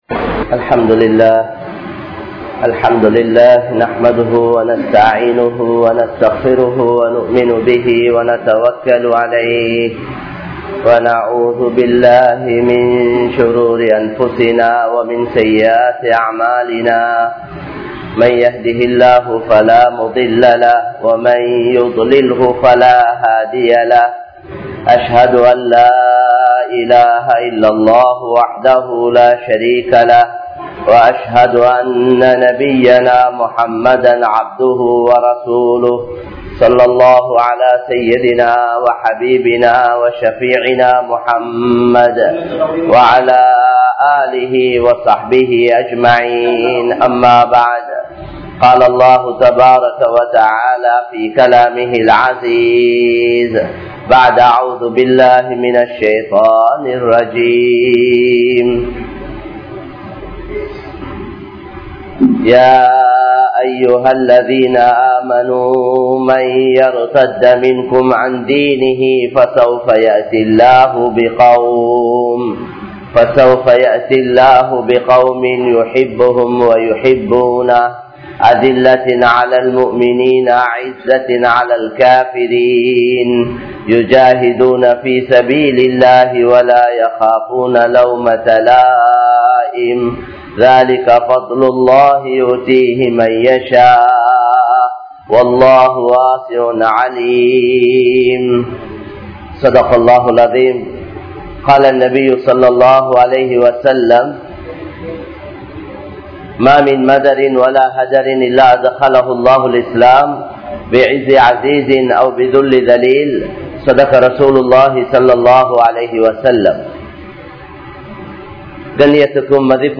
Mouthai Neasipavarhal Muslimkal | Audio Bayans | All Ceylon Muslim Youth Community | Addalaichenai